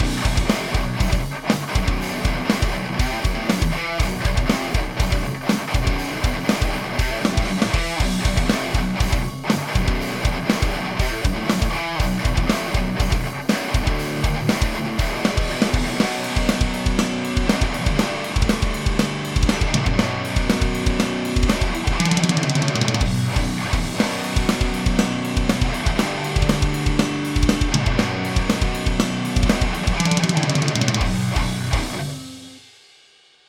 Bus stereo
Anche qui ho creato due appositi file audio, uno con tutti gli insert attivi nel bus
questo per far sentire come suonano in modo diverso le chitarre all'interno del mix.